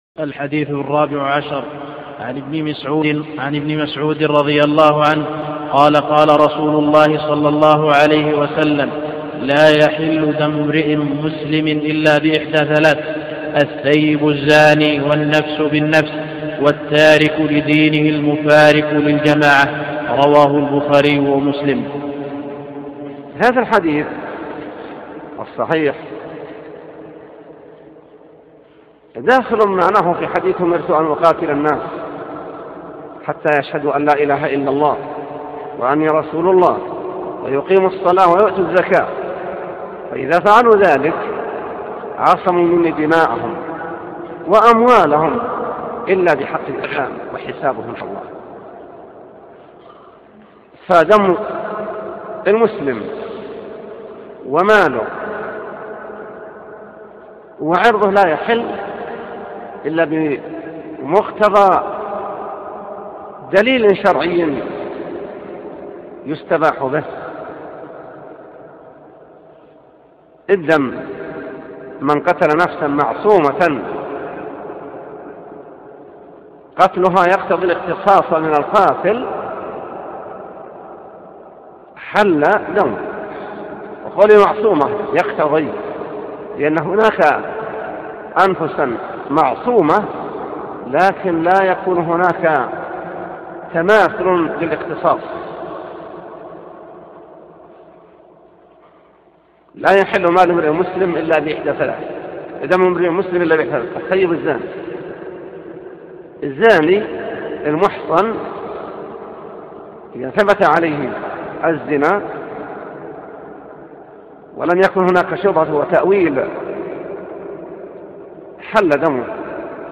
14 – شرح حديث لا يحل دم امرئ مسلم إلا بإحدى ثلاث – الشيخ : صالح اللحيدان